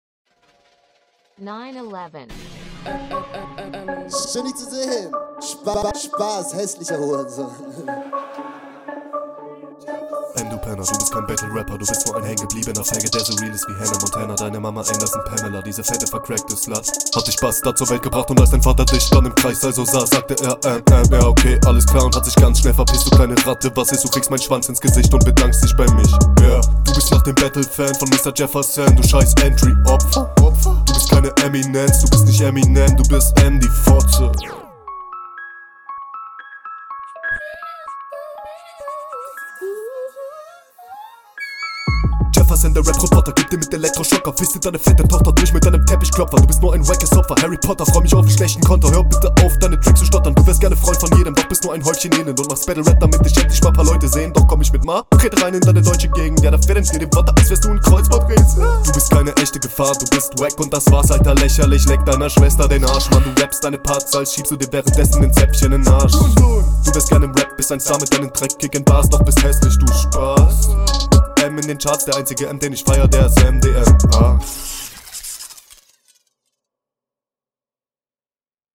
Geiler Beat mit lyrico - Einspieler! Du kommst dann mit nem ziemlich sicken Vibe rein!